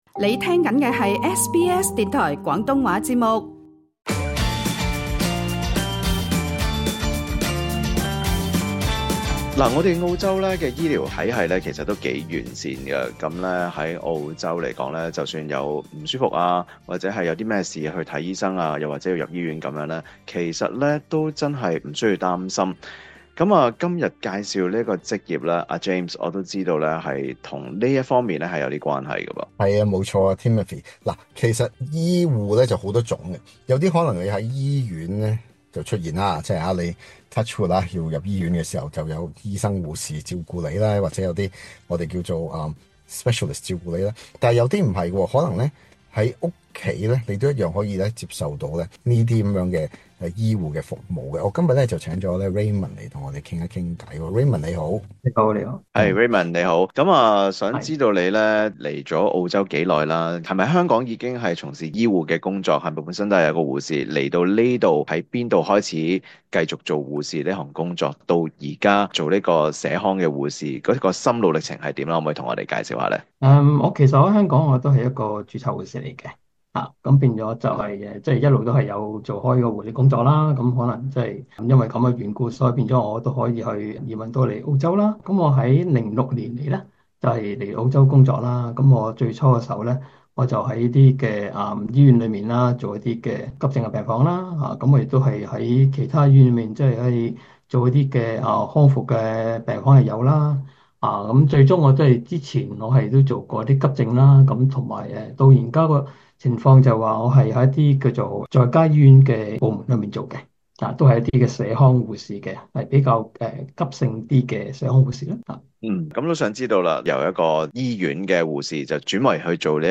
今集的【創業搵食GUIDE】，請來一位在澳洲的醫院服務多年後，近年轉為社區護士，分享兩者之間的分別。